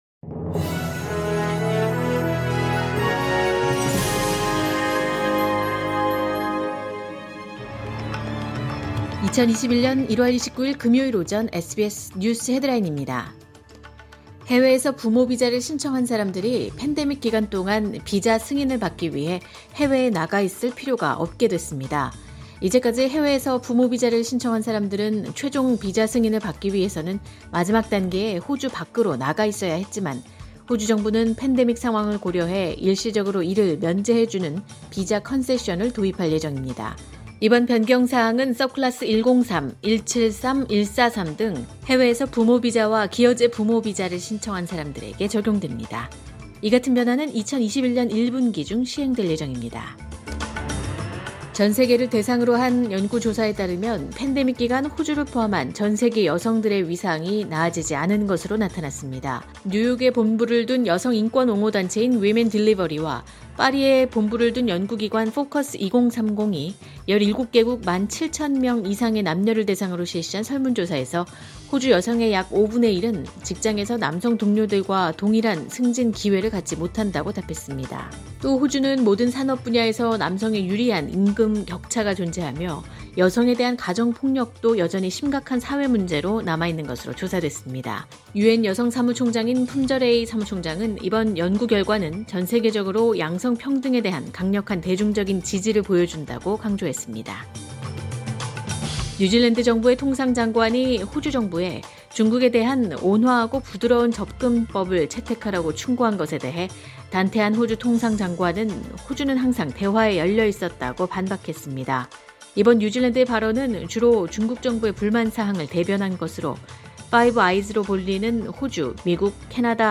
2021년 1월 29일 금요일 오전의 SBS 뉴스 헤드라인입니다.